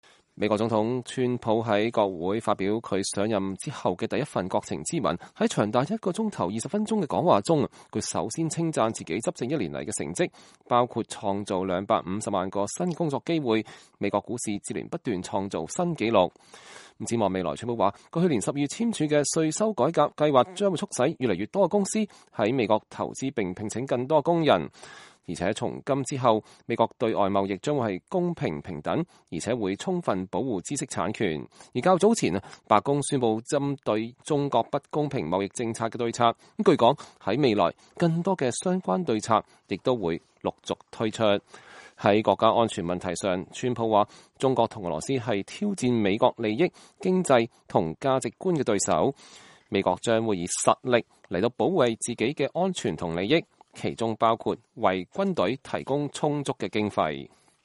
川普在講話中不時被掌聲打斷，但是在場民主黨人的反應明顯沒有共和黨人熱烈。